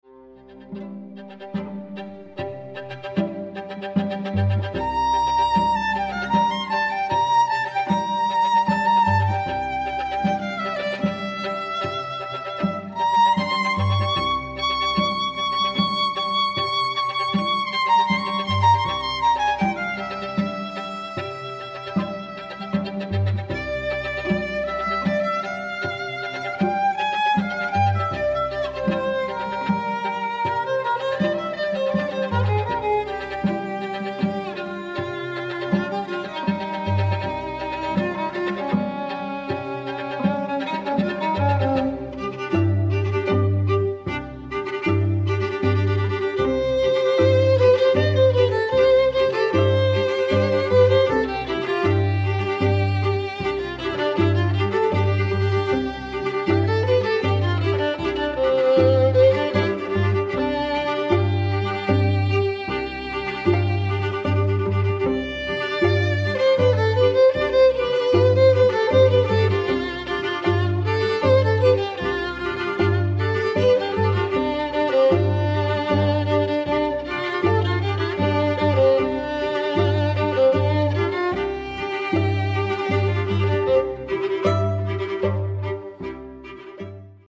String Quartet for Hire in the South West
This experienced string quartet consists of cello, viola and double violin - the perfect recipe for accompanying a wedding ceremony or entertaining guests during your drinks reception.